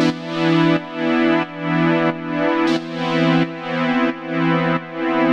Index of /musicradar/sidechained-samples/90bpm
GnS_Pad-MiscA1:4_90-E.wav